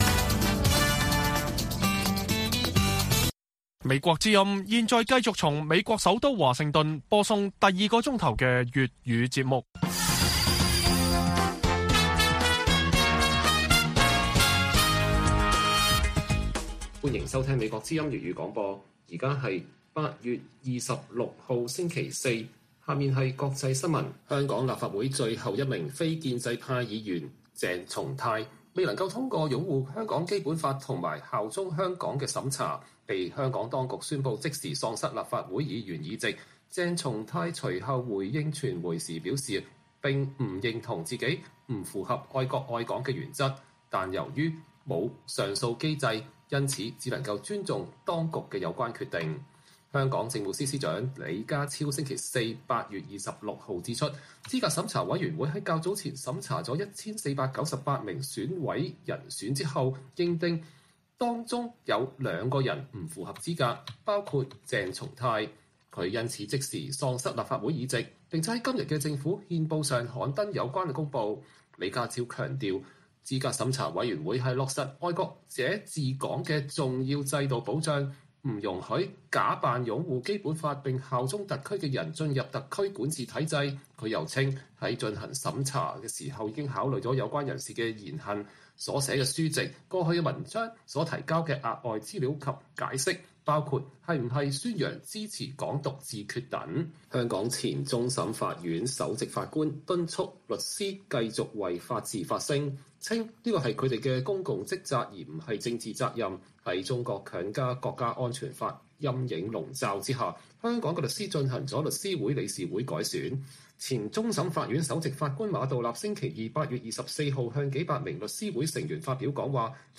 粵語新聞 晚上10-11點: 非建制派議員鄭松泰被當局宣布即時喪失議席